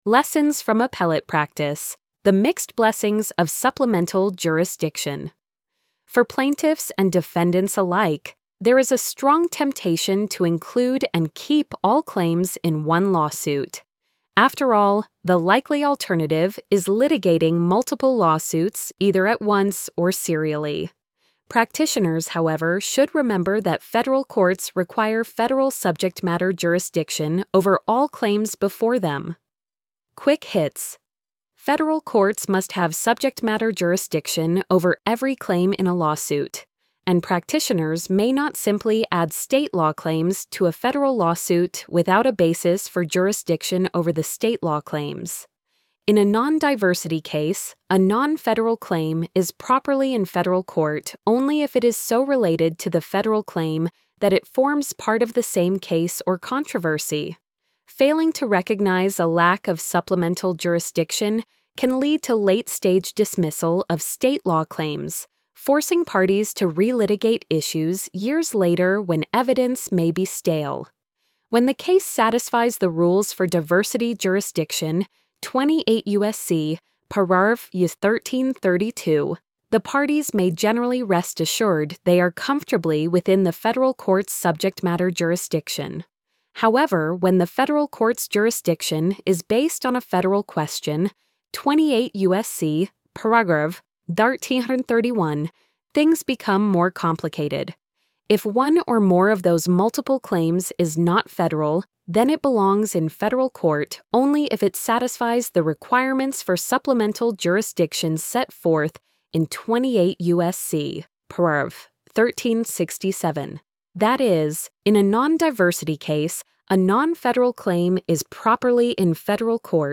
lessons-from-appellate-practice-the-mixed-blessings-of-supplemental-jurisdiction-tts.mp3